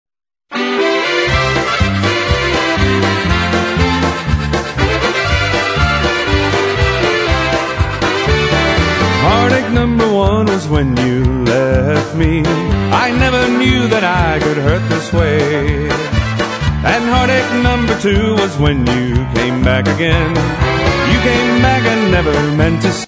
Current Location: Genre Polka - Variety